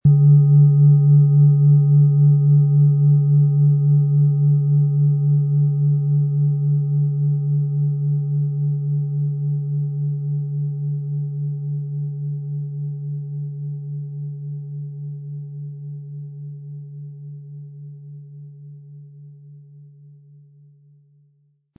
Tibetische Herz-Kopf- und Becken-Klangschale, Ø 20,1 cm, 1200-1300 Gramm, mit Klöppel
Um den Originalton der Schale anzuhören, gehen Sie bitte zu unserer Klangaufnahme unter dem Produktbild.
Aber uns würde der kraftvolle Klang und diese außerordentliche Klangschwingung der überlieferten Fertigung fehlen.
MaterialBronze